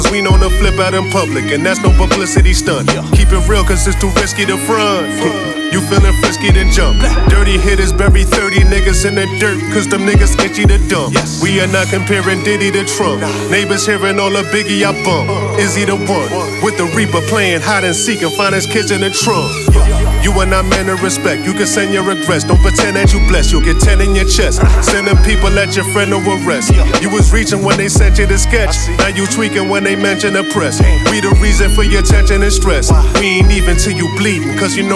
Жанр: Хип-Хоп / Рэп